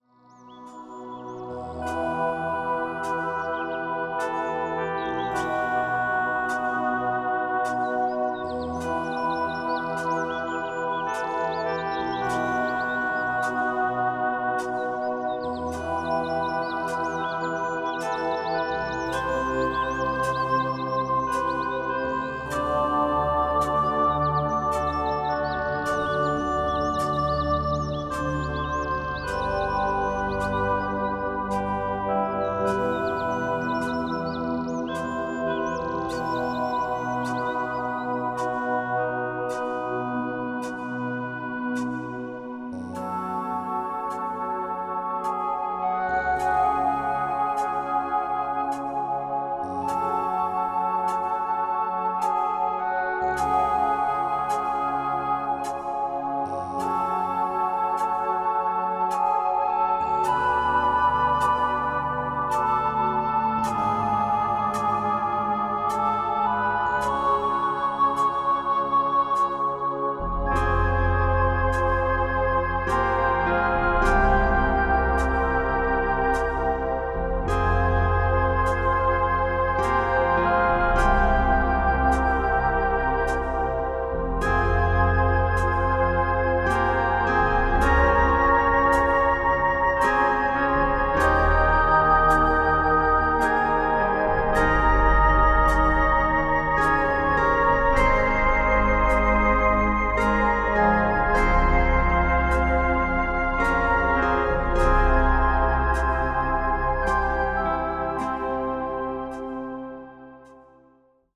varied, nice melodies and sounds from nature
many themes and instruments, still and enthousiastic as well